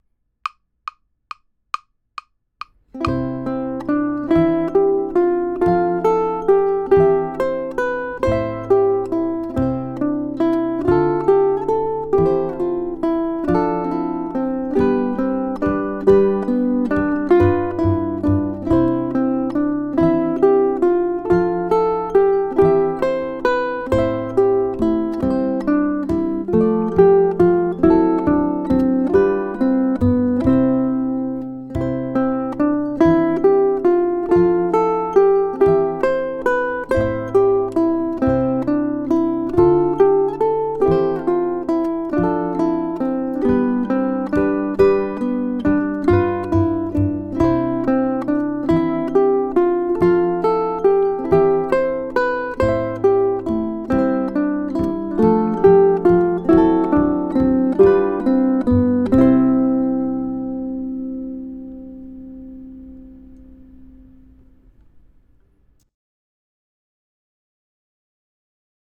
Due to the wide range of Bach's melody, this arrangement is optimized for ʻukuleles in low G tuning.
The constant motion, pinkie stretches to the low G string, and triple meter feel can be taxing, so take it slow at first.
Jesu, Joy of Man's Desiring sounds best performed at a brisk allegro tempo (120 BPM+).
You'll hear a C chord on beat 1 and should begin the melody on beat 2.